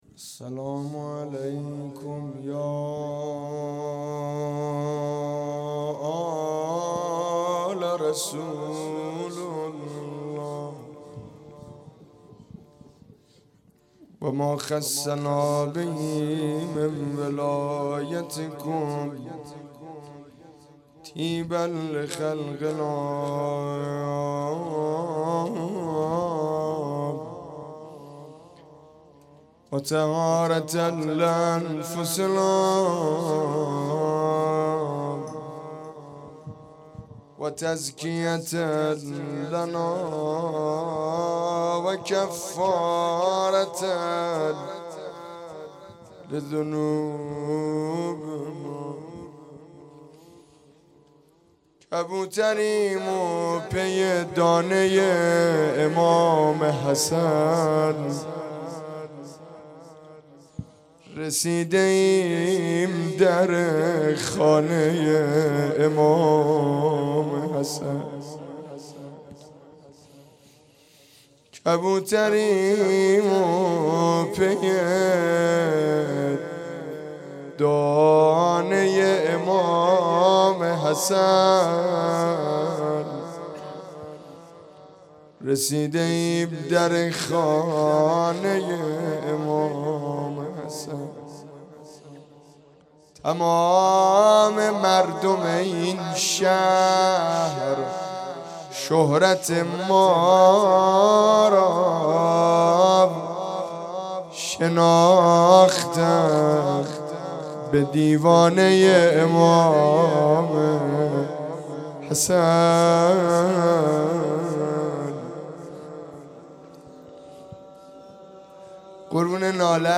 مدح و مناجات
اقامه عزای رحلت پیامبر اکرم و شهادت امام حسن مجتبی علیه السلام